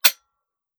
12ga Pump Shotgun - Dry Trigger 002.wav